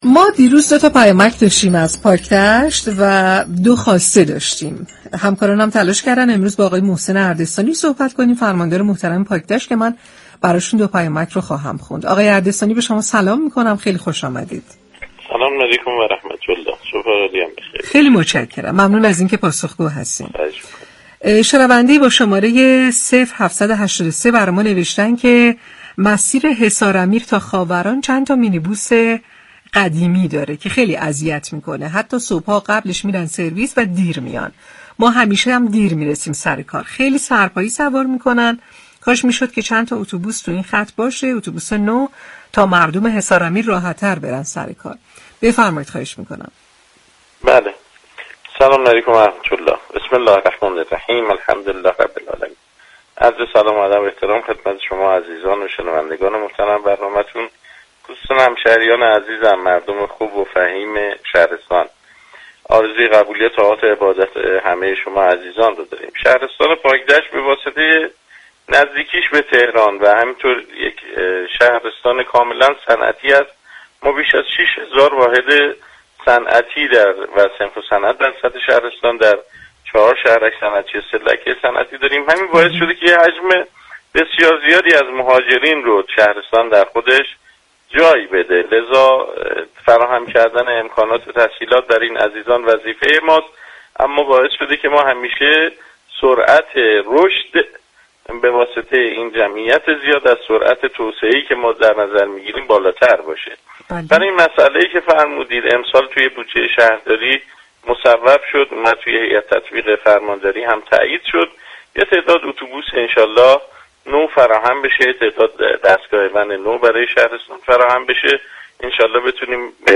در برنامه امروز 27 فروردین ماه ساكنان شهرستان پاكدشت مشكلات متعدد این شهرستان را مطرح كرده و خواستار پیگیری مطالبات خود از سوی مسئولان شدند.